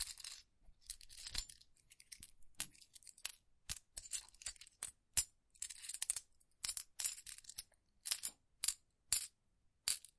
硬币 货币的声音 " 数钱 1
描述：计算硬币形式的变化。录制为16位立体声WAV文件。
标签： 货币 改变 计数 硬币
声道立体声